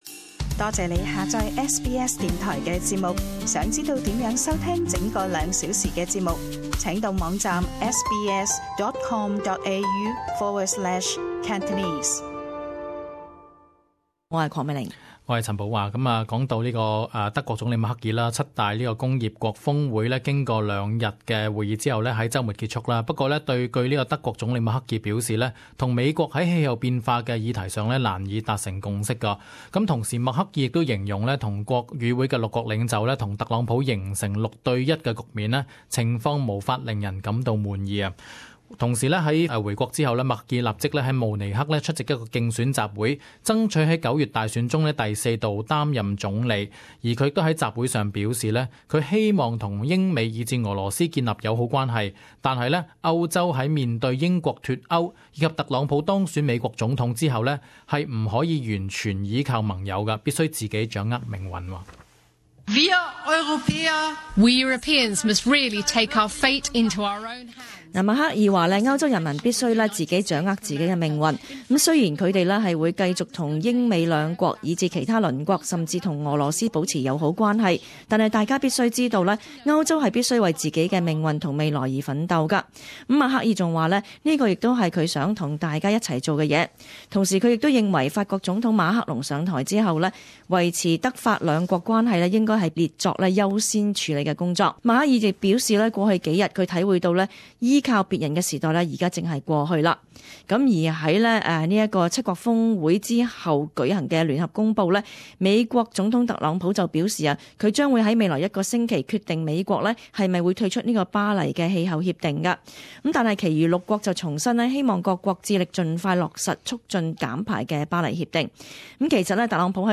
【時事報導】默克爾: 歐洲不能再依賴英美